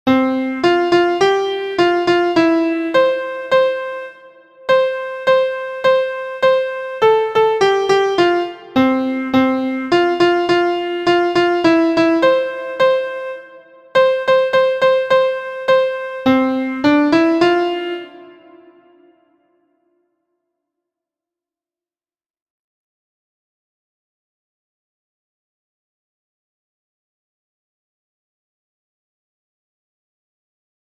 Extended range, descending dominant octave skip,
and a common ending.
• Origin: England – Nursery Rhyme
• Key: F Major
• Time: 4/4
• Form: ABaC